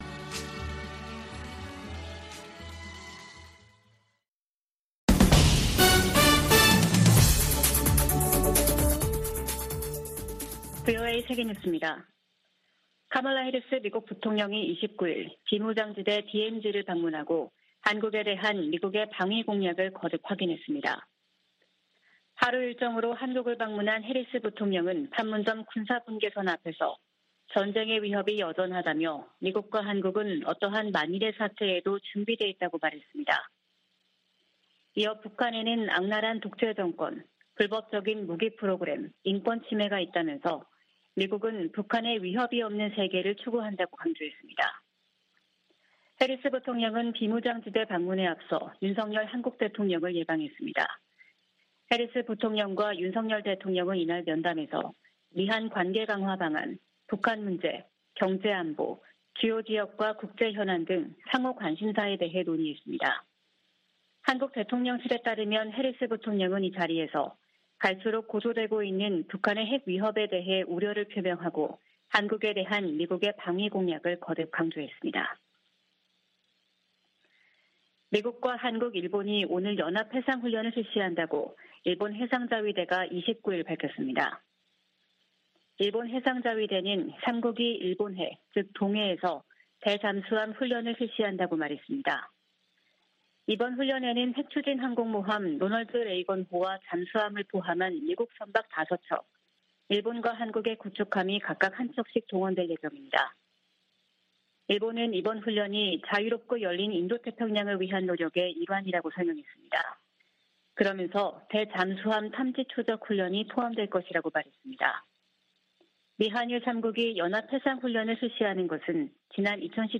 VOA 한국어 아침 뉴스 프로그램 '워싱턴 뉴스 광장' 2022년 9월 30일 방송입니다. 한국을 방문한 카멀라 해리스 미국 부통령이 윤석열 한국 대통령을 만나 미국의 철통같은 방위공약을 재확인했습니다.